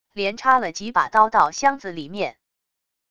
连插了几把刀到箱子里面wav下载